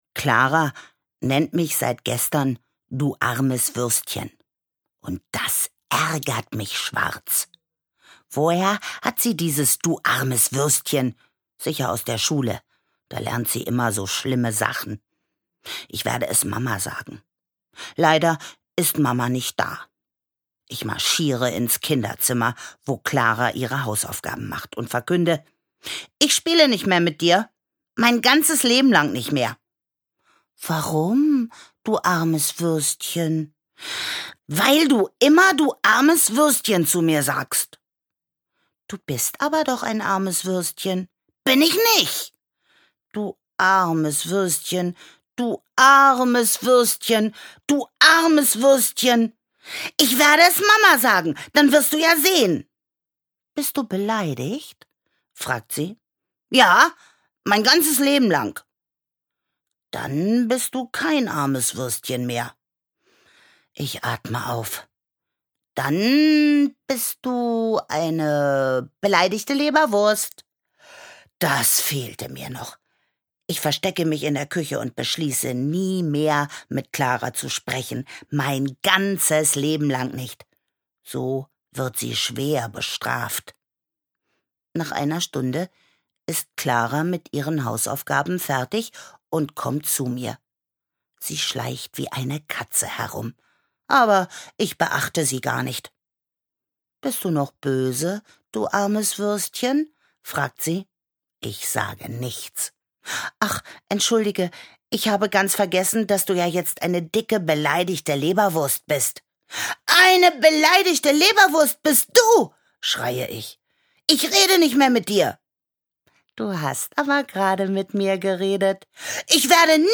Hörbuch: Ich und meine Schwester Klara.